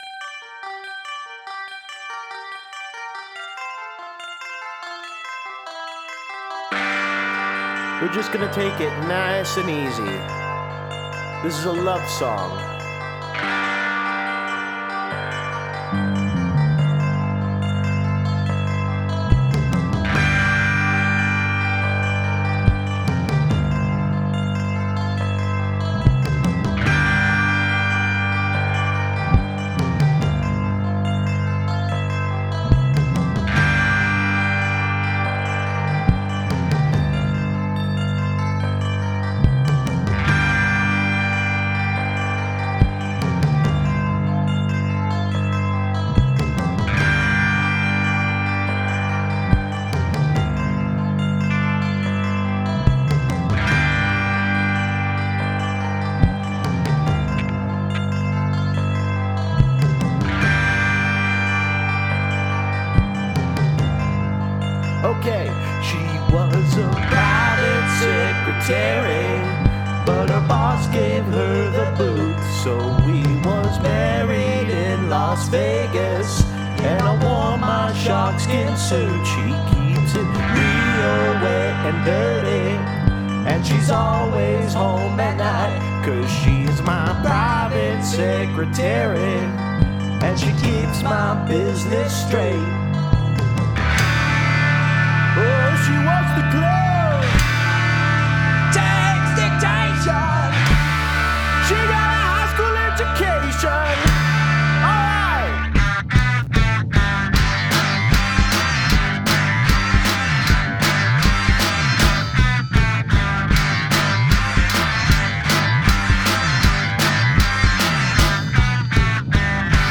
All the harmony vocals were performed by me